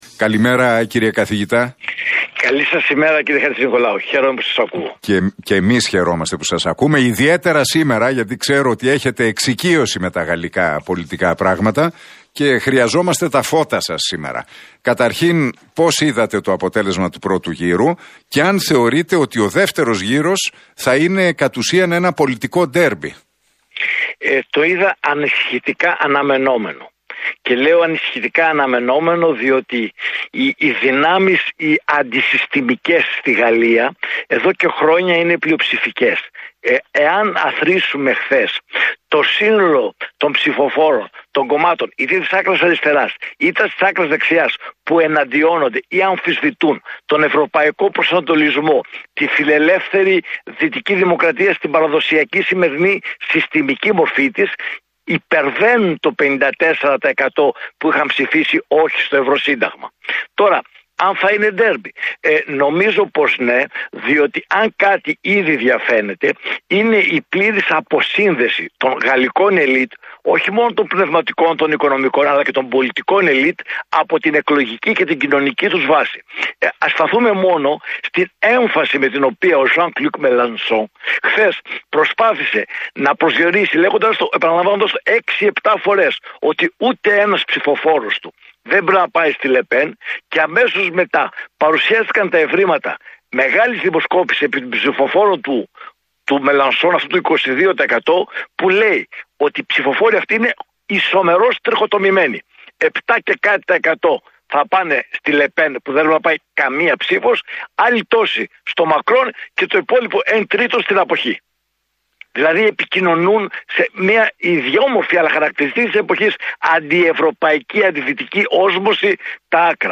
μίλησε στον Realfm 97,8 και τον Νίκο Χατζηνικολάου για τα αποτελέσματα του πρώτου γύρου των γαλλικών εκλογών.